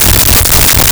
The Main Reactor Loop 02
The Main Reactor Loop 02.wav